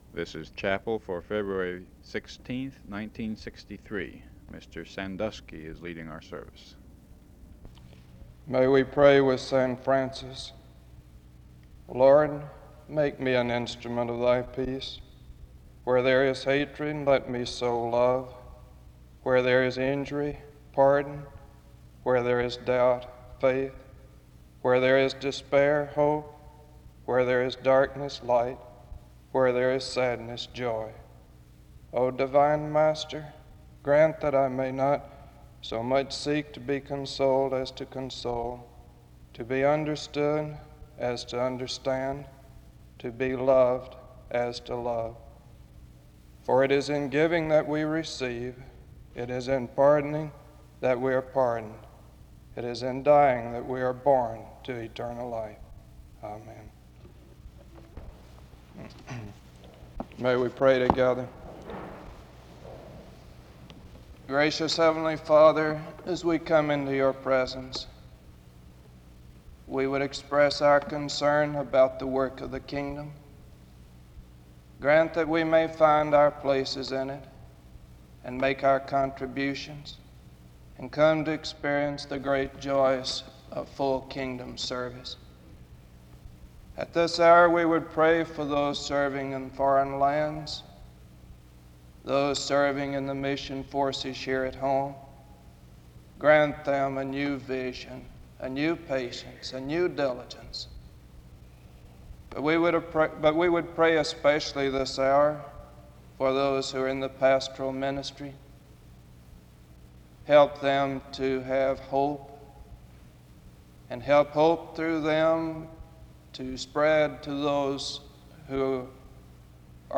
The service begins with a prayer from St. Francis from 0:00-0:56. A prayer is offered from 1:00-4:07. A special solo performance of Psalm 23 is sung from 4:20-9:37.